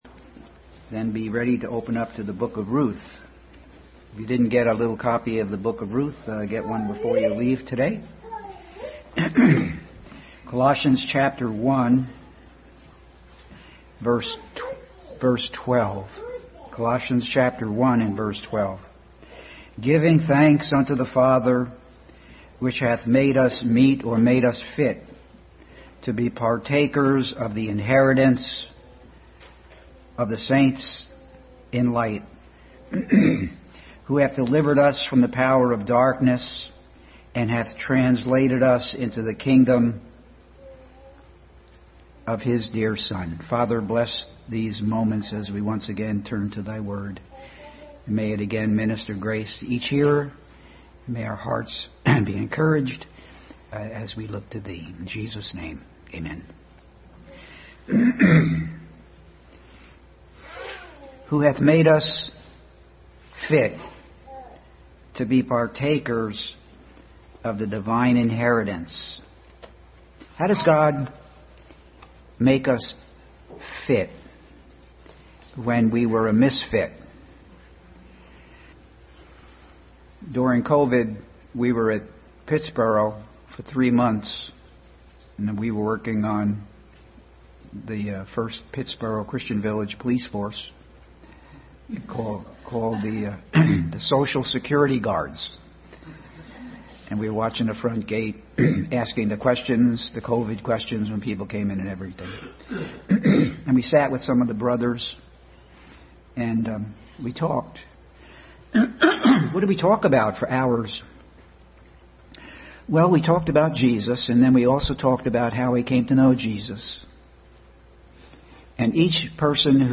Family Bible Hour message.